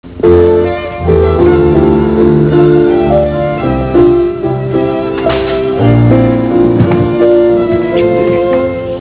Classical/Instrumental
Comment: strings